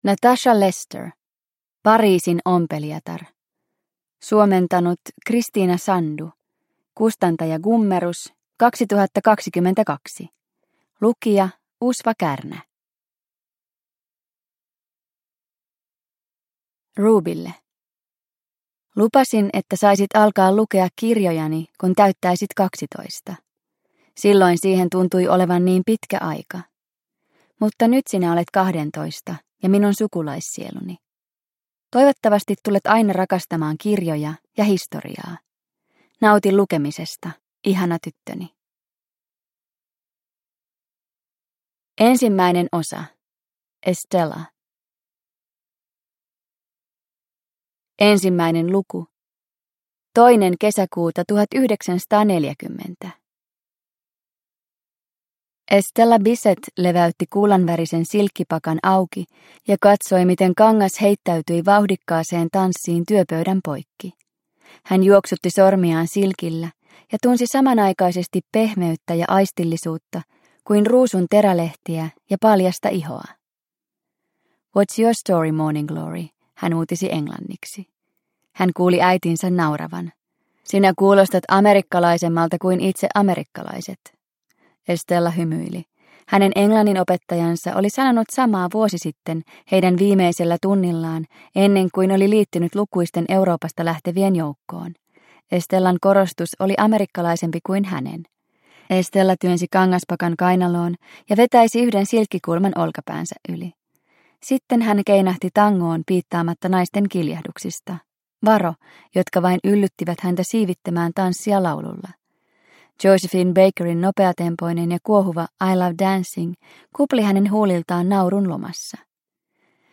Pariisin ompelijatar – Ljudbok – Laddas ner